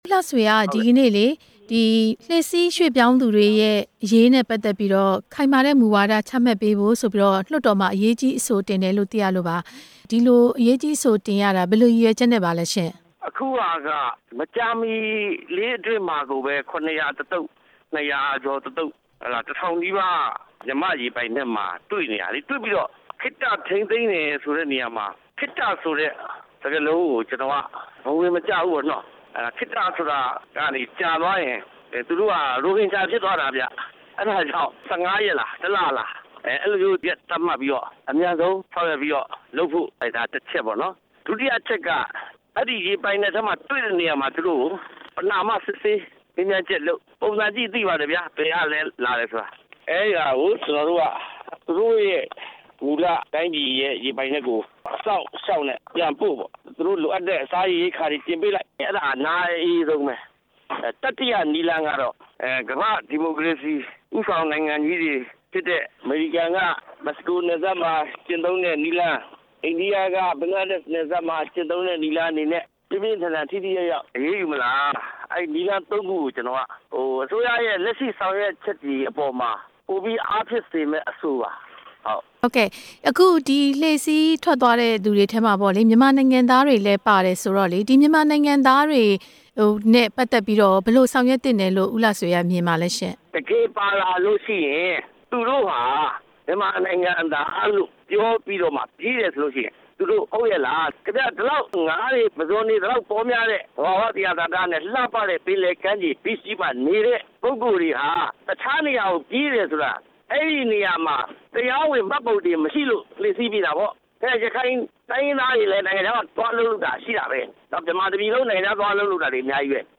ဦးလှဆွေကို မေးမြန်းချက်